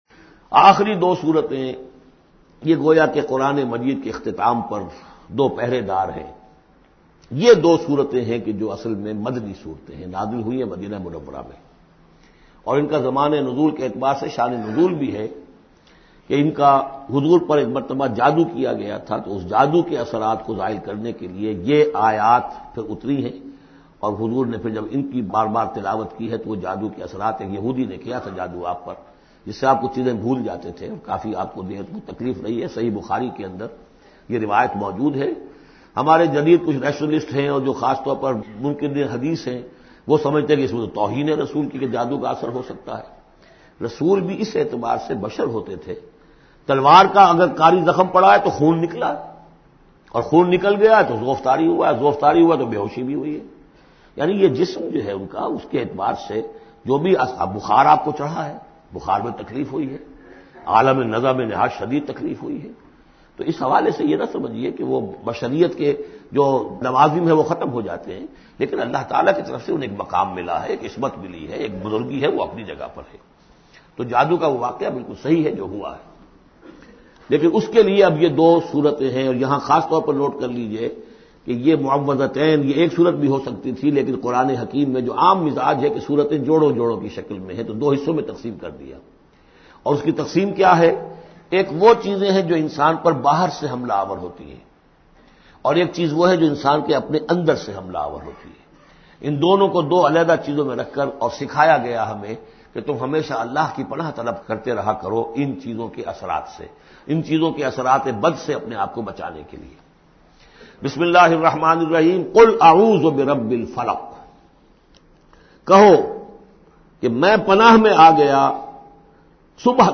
Surah Falaq Audio Tafseer by Dr Israr Ahmed
Surah Falaq is 113 chapter of Holy Quran. Listen online mp3 audio tafseer of Surah Falaq in the voice of Dr Israr Ahmed.